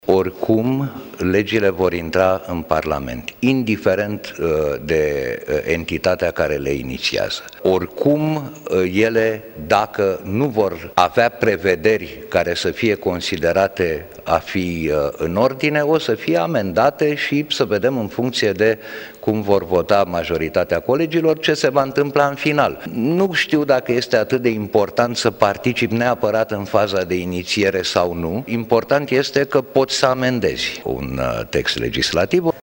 Liderul grupului minorităților din Camera Deputaților, Varujan Pambuccian, a declarat că nu este important ce formațiune politică inițiază modificările la legislația în acest domeniu pentru că textul poate fi modificat: